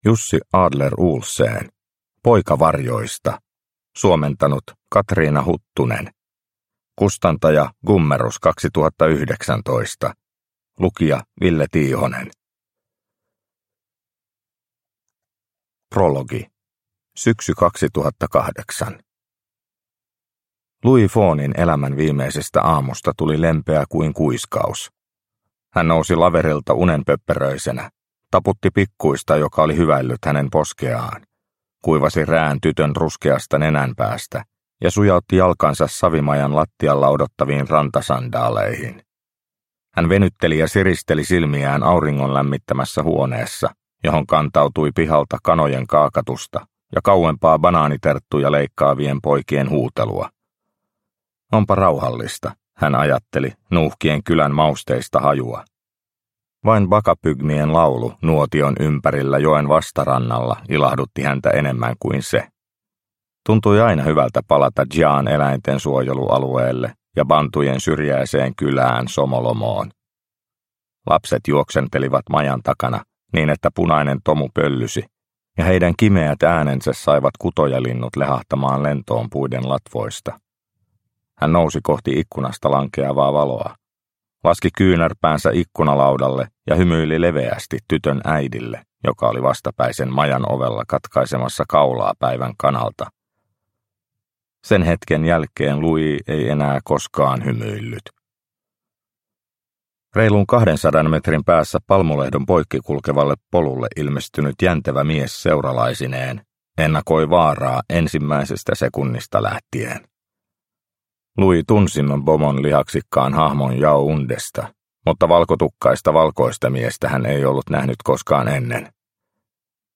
Poika varjoista – Ljudbok – Laddas ner